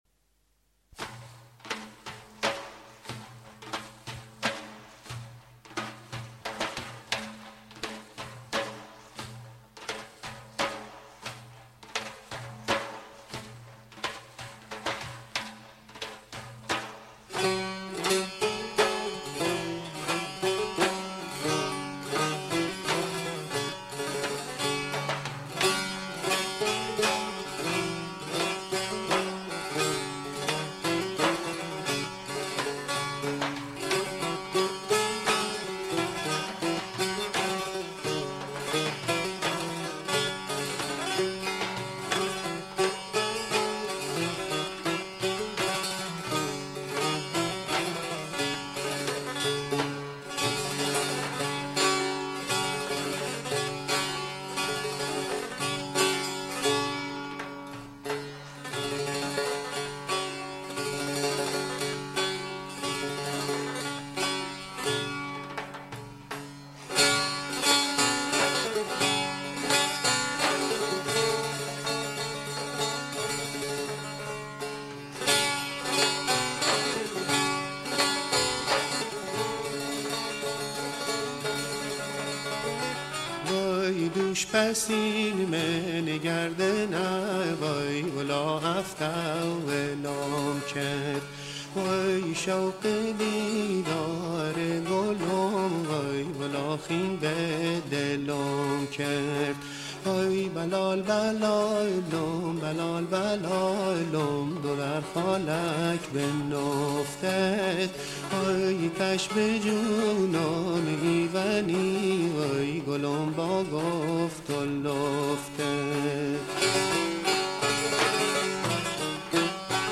که برای علاقه مندان به موسیقی محلی بختیاری آماده کرده ایم.